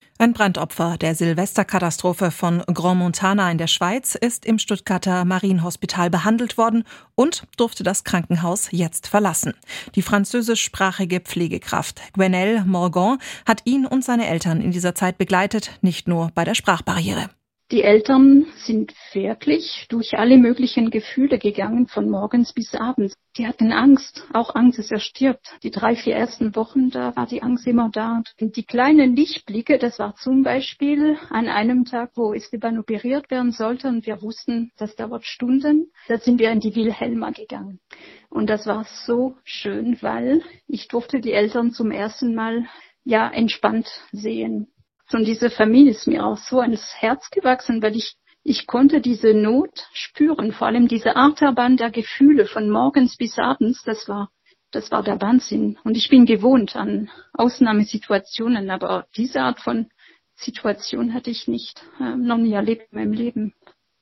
Im SWR-Interview erzählt sie, wie sie den Eltern eine Stütze sein konnte und was sie aus der Zeit mitgenommen hat.